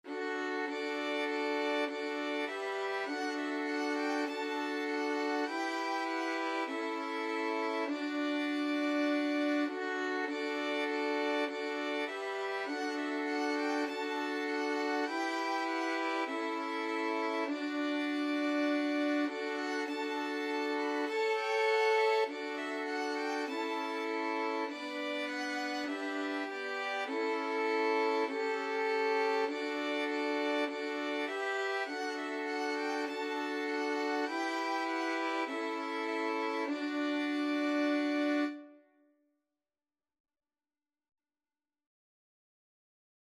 Violin 1Violin 2Violin 3Violin 4
4/4 (View more 4/4 Music)
Violin Quartet  (View more Easy Violin Quartet Music)